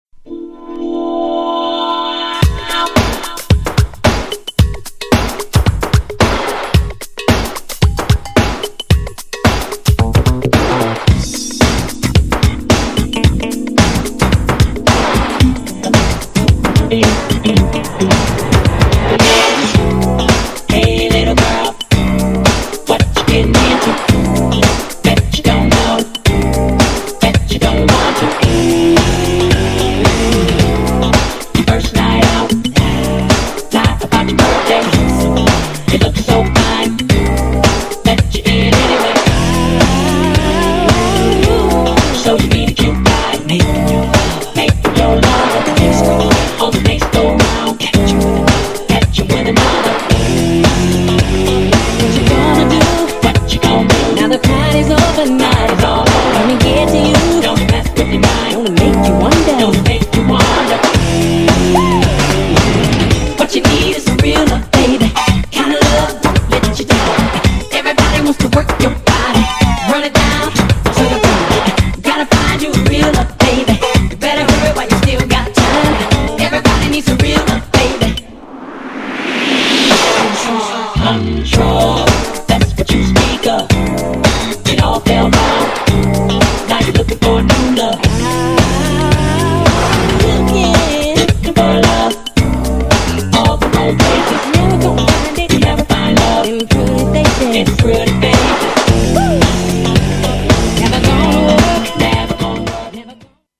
GENRE R&B
BPM 111〜115BPM